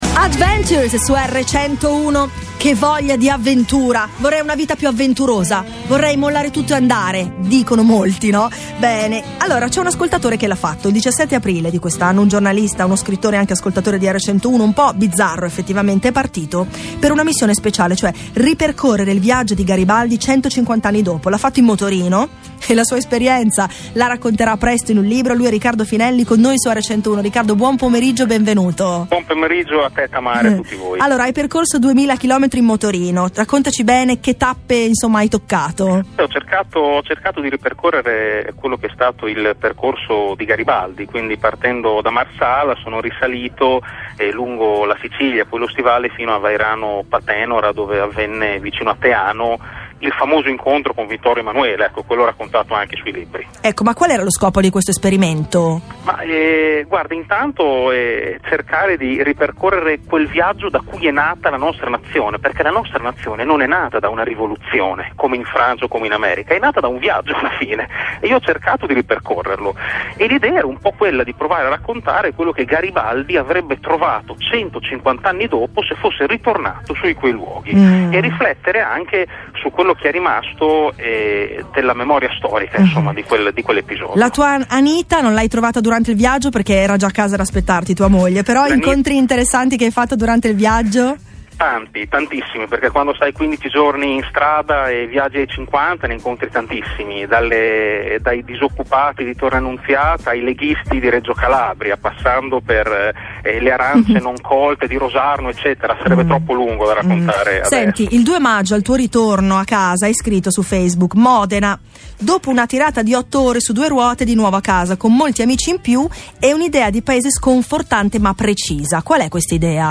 Intervista a Radio 101